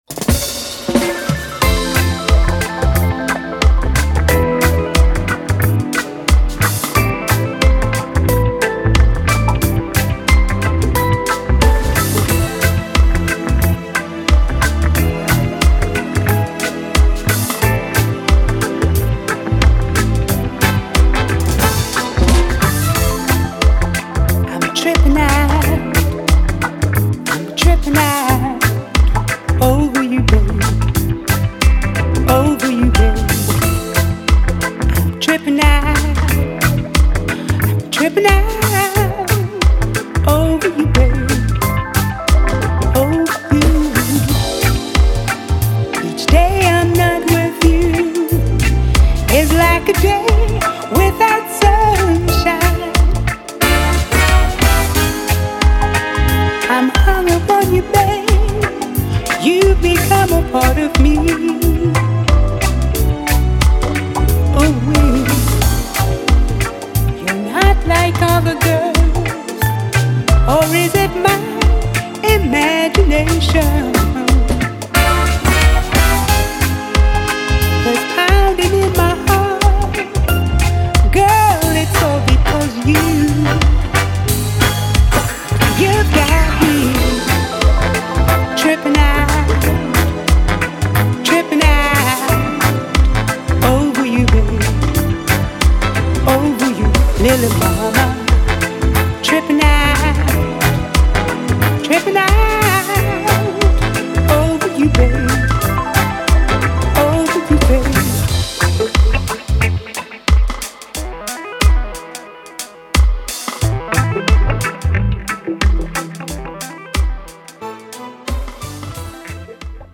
リマスタリングを施しパワーアップしたサウンドで再登場します。
ジャンル(スタイル) REGGAE / SOUL